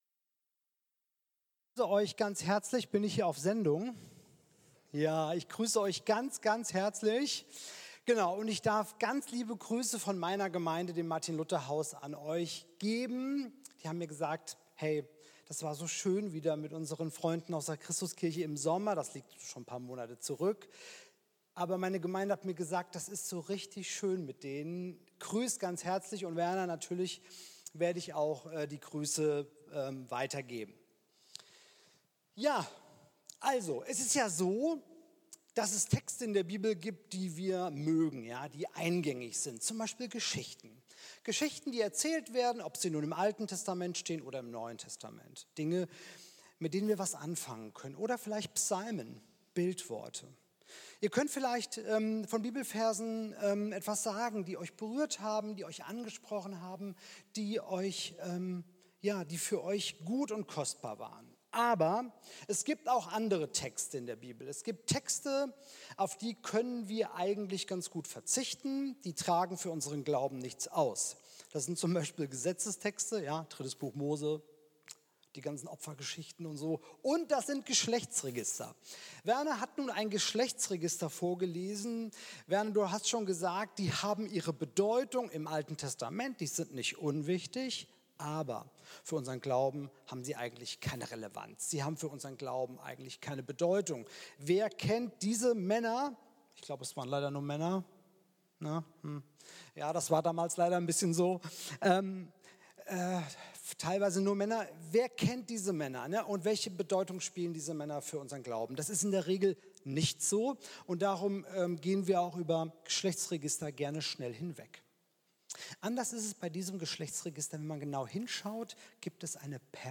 Eine ermutigende Predigt über Vertrauen, Wachstum und Gottes Nähe mitten im Kummer.